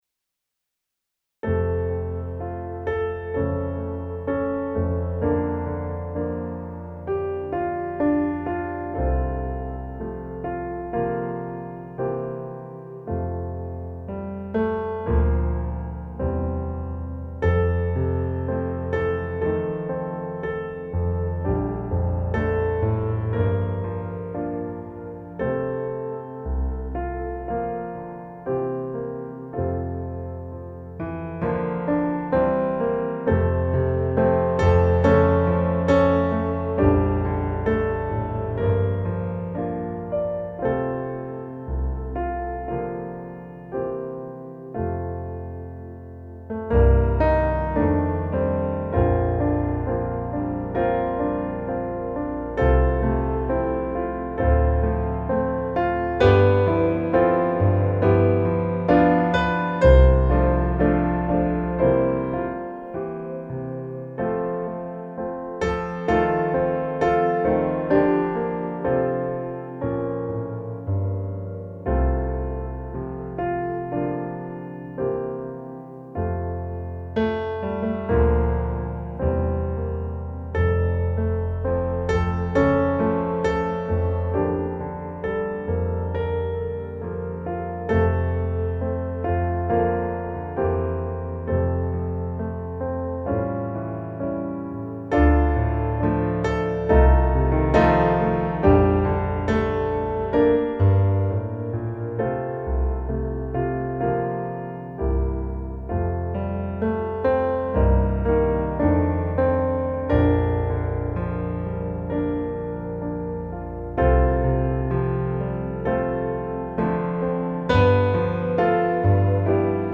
Gemensam sång
Musikbakgrund Psalm